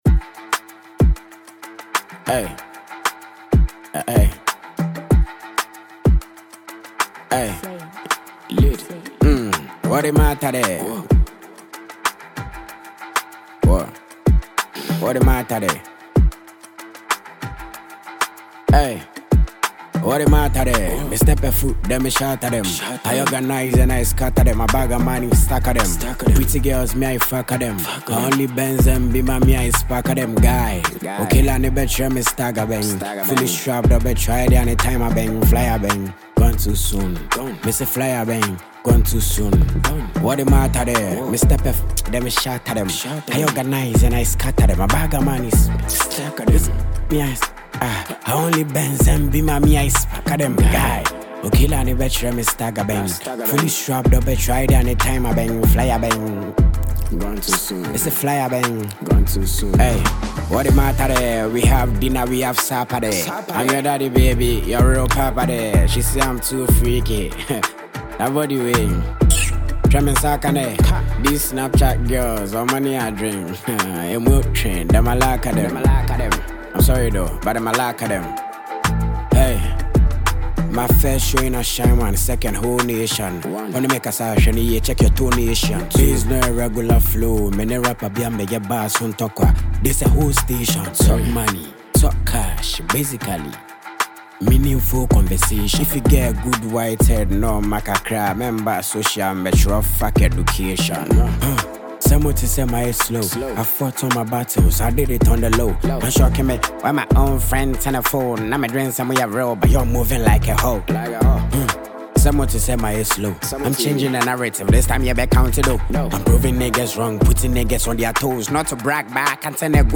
Check the new banger from Ghanaian rapper